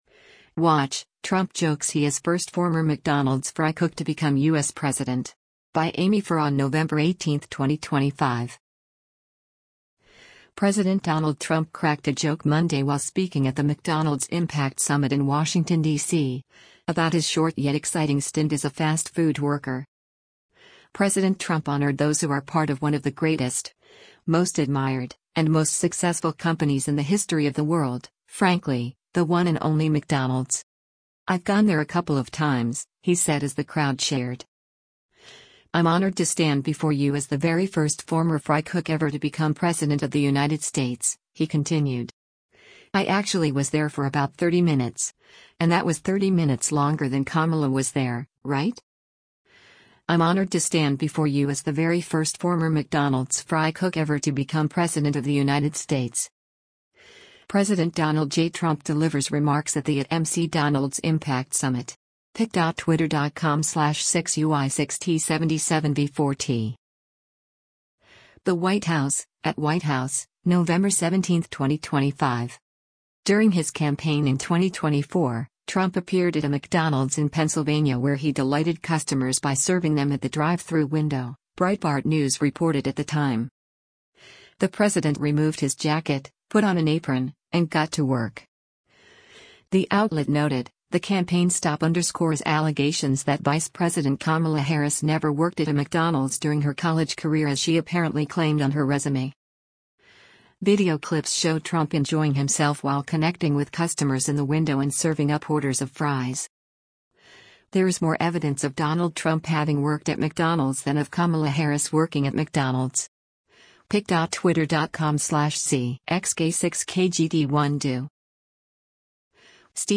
President Donald Trump cracked a joke Monday while speaking at the McDonald’s Impact Summit in Washington, DC, about his short yet exciting stint as a fast food worker.
“I’ve gone there a couple of times,” he said as the crowd cheered.